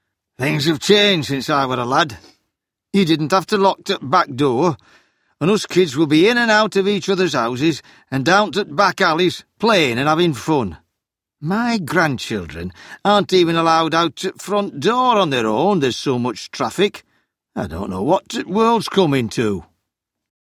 Then listen to four people from different parts of England.
Just listen to their accents.